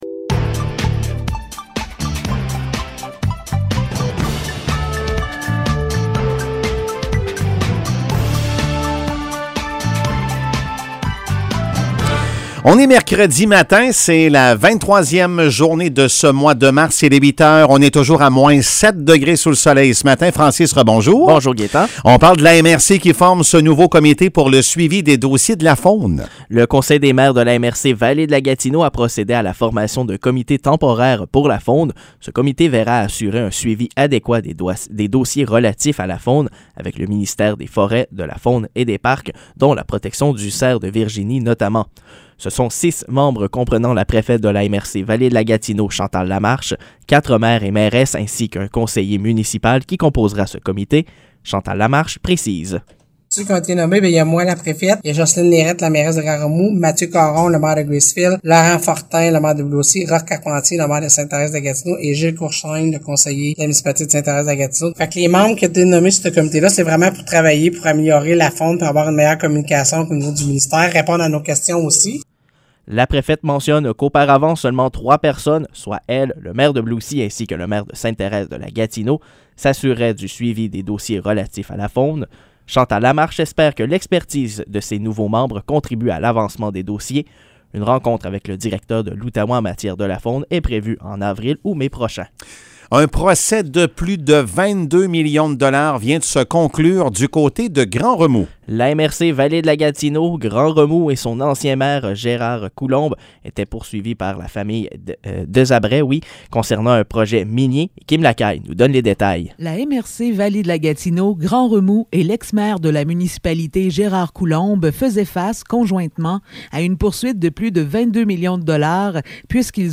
Nouvelles locales - 23 mars 2022 - 8 h